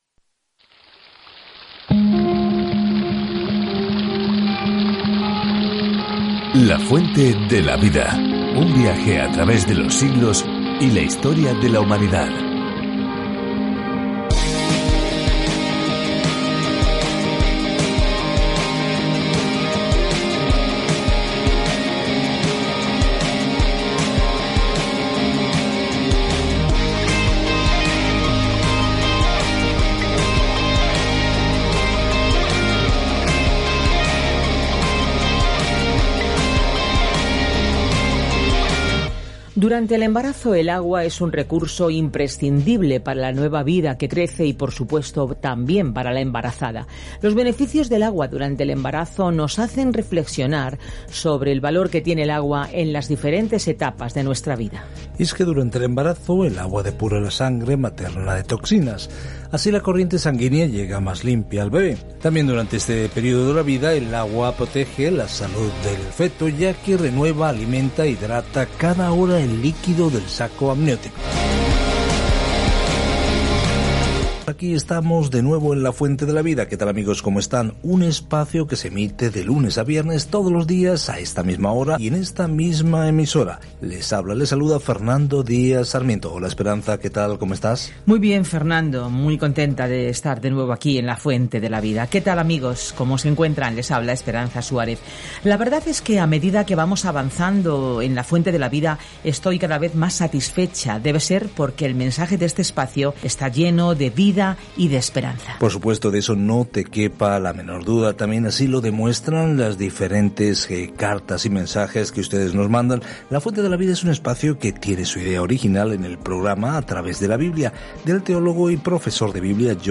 Escritura HABACUC 2:1-3 Día 4 Iniciar plan Día 6 Acerca de este Plan Habacuc pregunta con un gran “¿por qué, Dios?” Al comienzo de una serie de preguntas y respuestas con dios sobre cómo trabaja en un mundo malvado. Viaja diariamente a través de Habacuc mientras escuchas el estudio en audio y lees versículos seleccionados de la palabra de Dios.